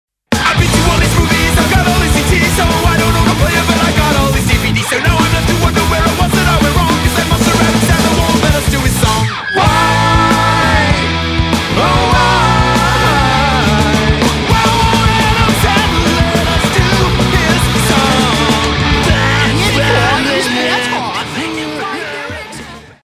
A very cool, in-your-face quick, thrashy punk EP.